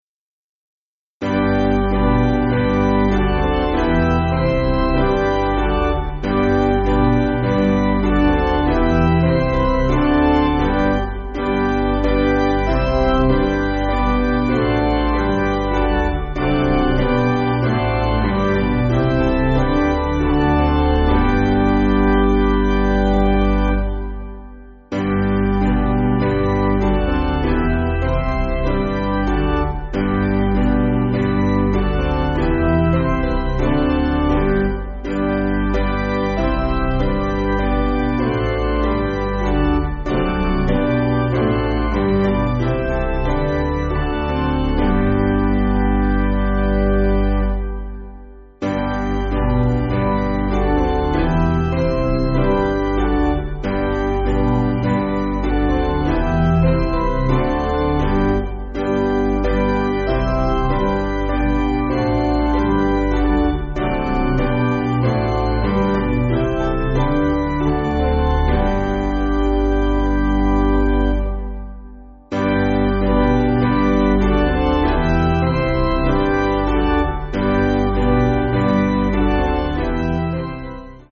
Basic Piano & Organ
(CM)   5/G